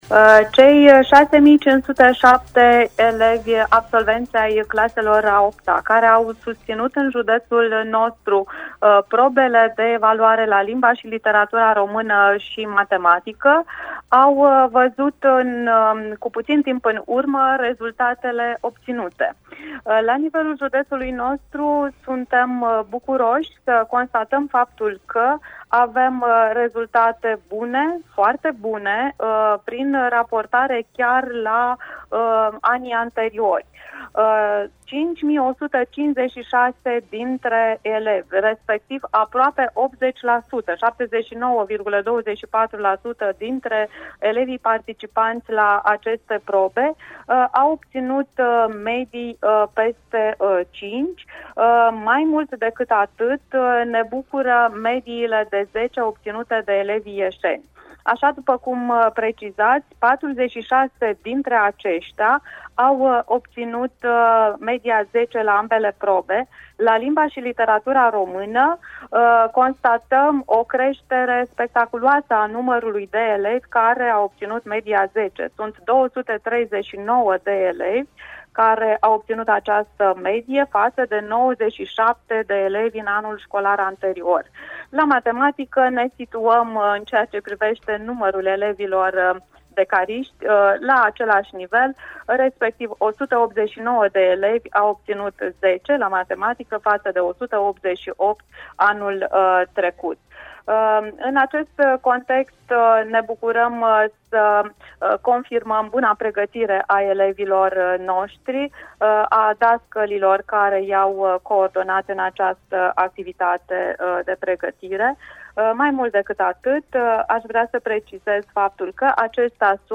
a sintetizat intr-o declaratie audio pentru 7est